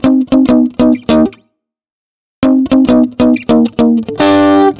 Сэмпл гитары в стиле Funk — Latin Funk
Тут вы можете прослушать онлайн и скачать бесплатно аудио запись из категории «70's Funk».
Sound_17380_LatinFunk.ogg